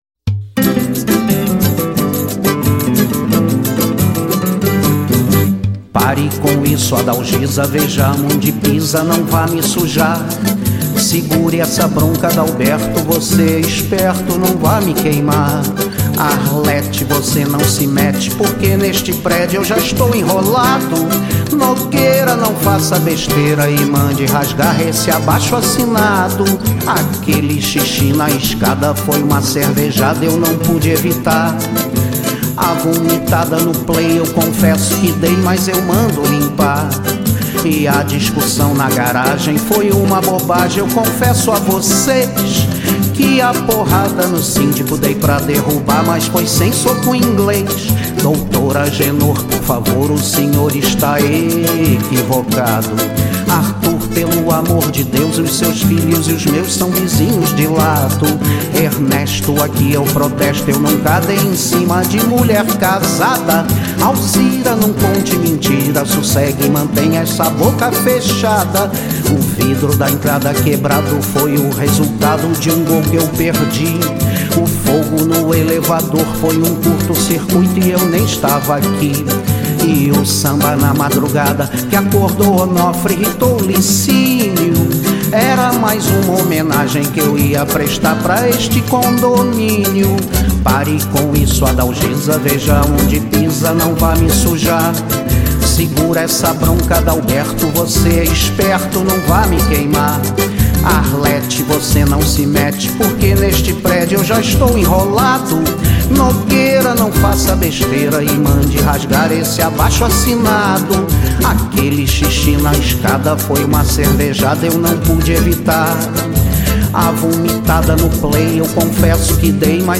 samba carioca